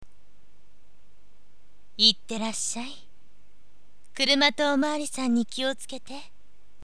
声：